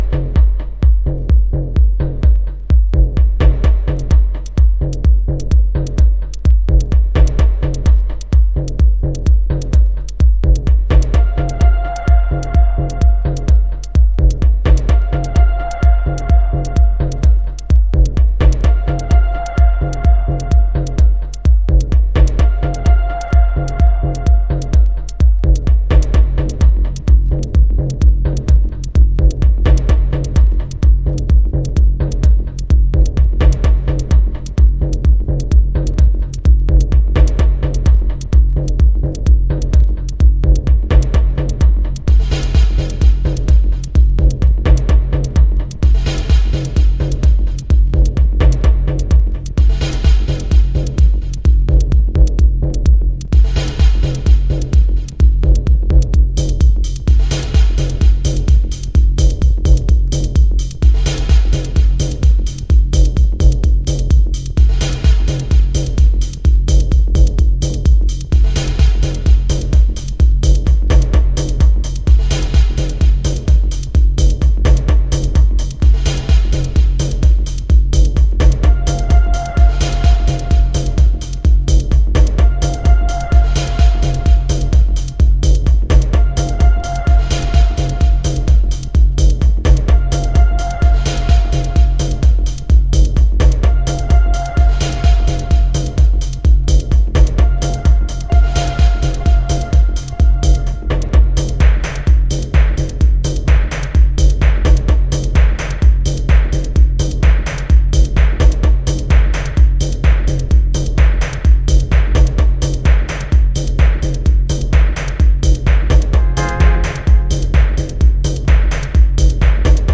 House / Techno
FORMAT - 12inch